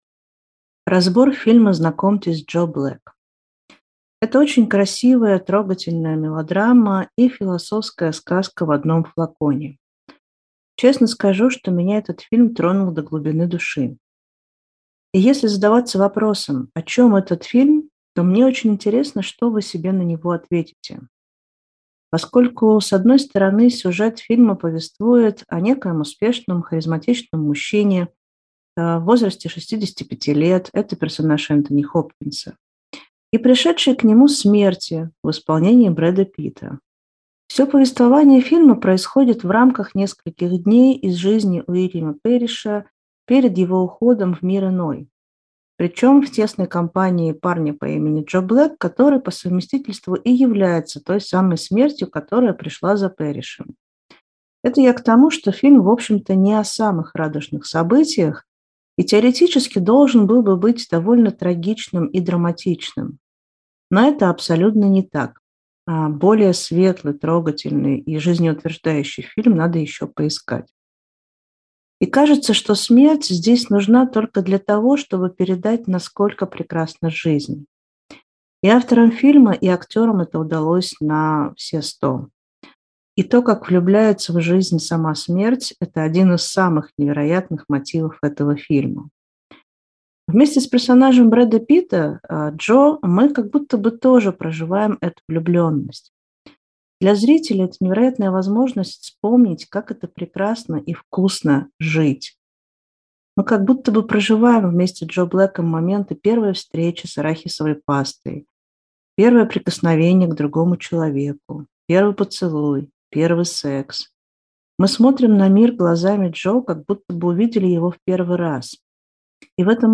Для этого разбора фильма доступна его аудиозапись